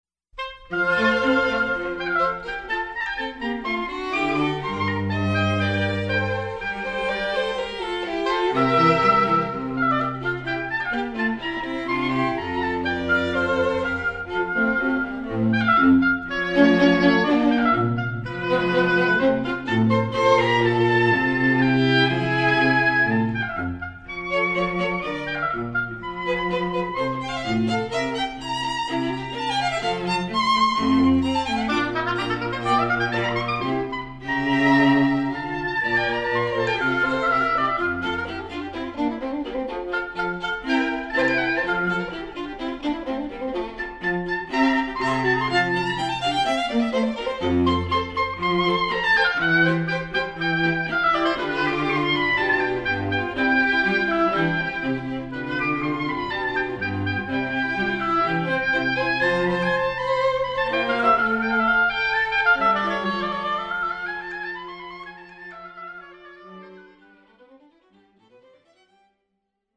ＡＢＯＢＡ四重奏団演奏会
ところ：箕島文化福祉センター
チェロ：（京都市交響楽団）
Ob.Quart.mp3